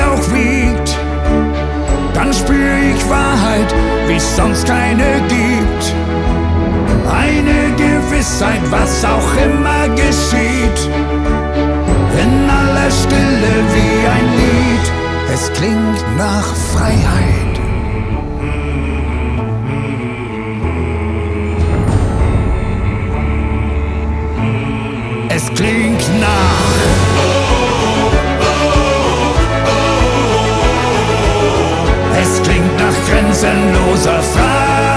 Жанр: Фолк-рок
# German Folk